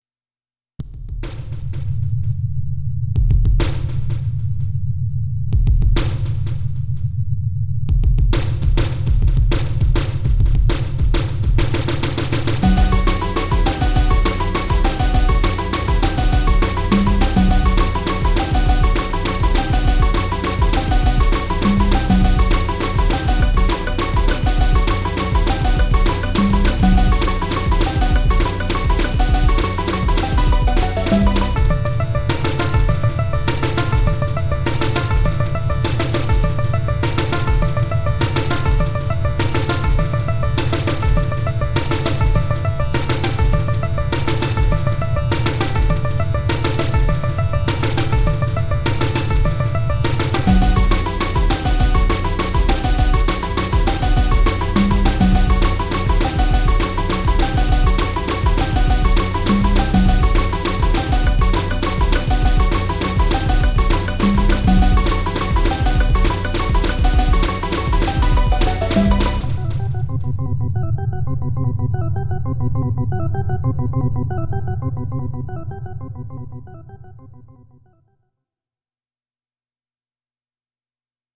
今見ると予想以上に落ち着いてますね。
なんていうかイントロ曲です。ちゅんちゅんドラムが印象的でBメロがなんか素敵。